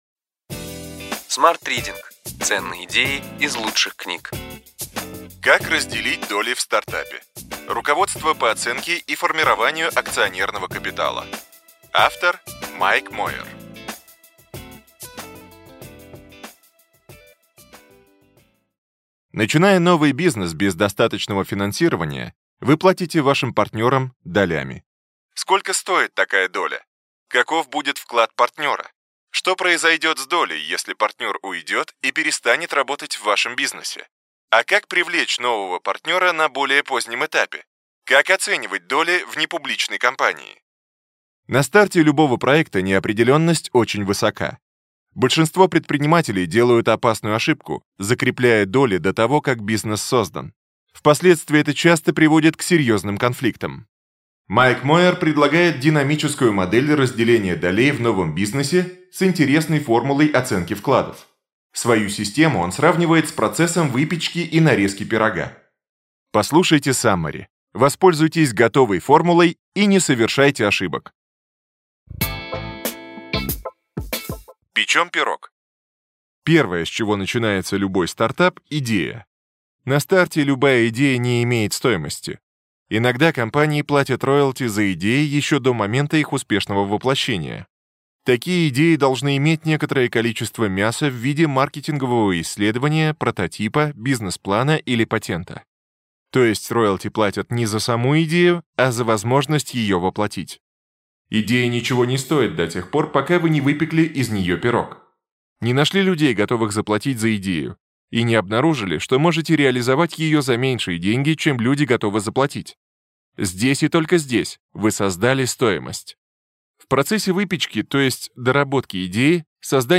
Аудиокнига Ключевые идеи книги: Как разделить доли в стартапе.